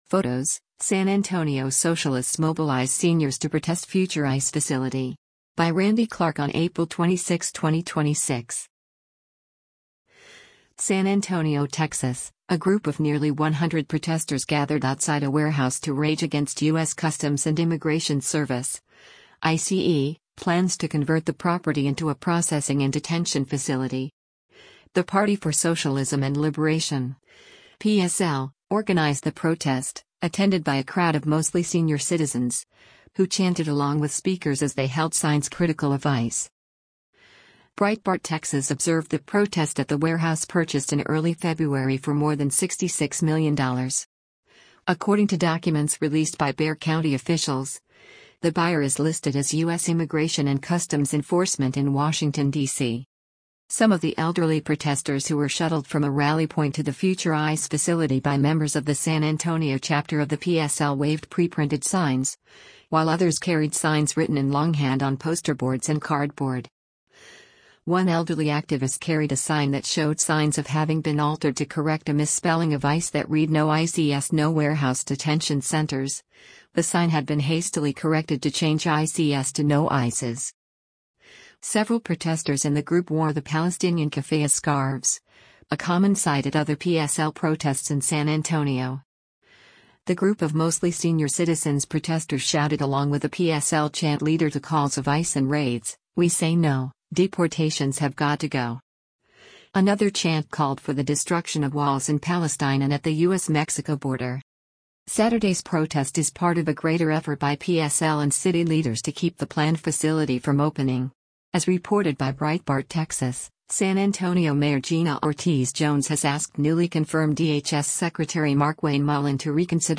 The Party for Socialism and Liberation (PSL) organized the protest, attended by a crowd of mostly senior citizens, who chanted along with speakers as they held signs critical of ICE.
The group of mostly senior citizens protesters shouted along with a PSL chant leader to calls of “Ice and Raids, we say no, deportations have got to go”. Another chant called for the destruction of walls in Palestine and at the U.S.-Mexico border.